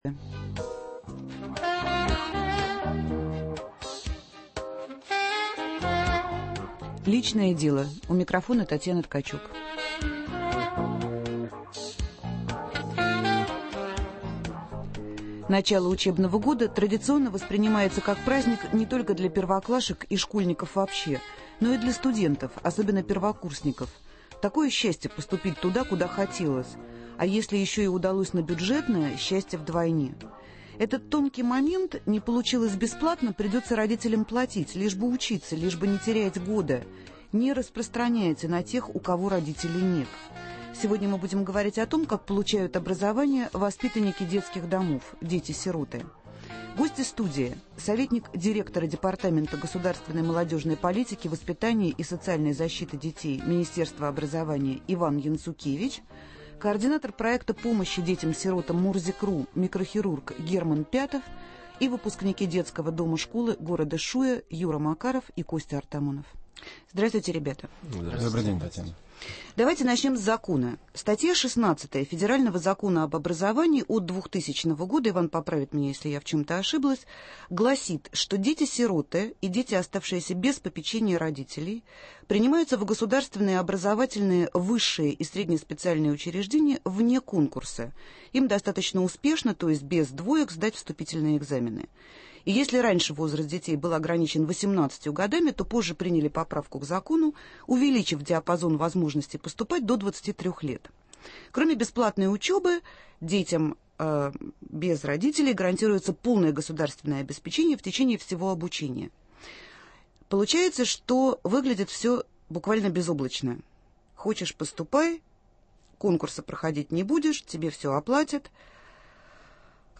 Почему среди сирот так мало людей с высшим образованием? На эфир прглашены представители Департамента государственной молодежной политики и социальной защиты детей Министерства образования и науки РФ; бывшие воспитанники детских домов и представители благотворительных организаций.